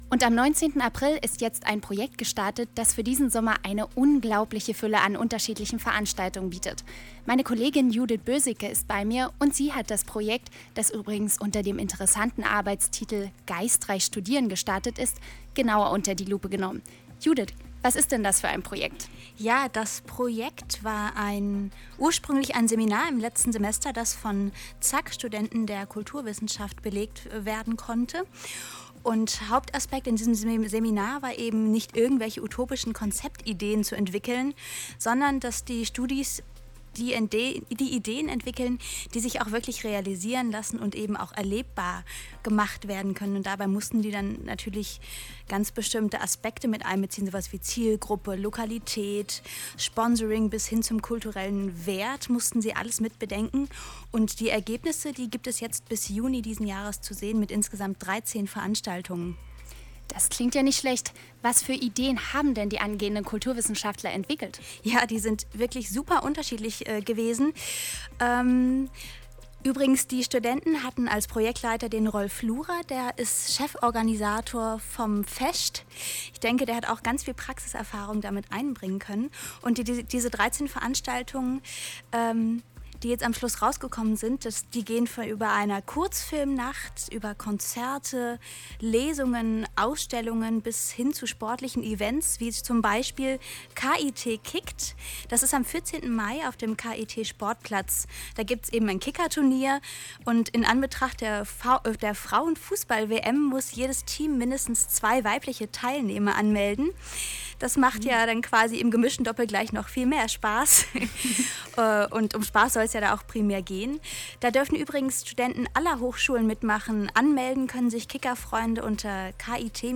Beiträge rund ums KIT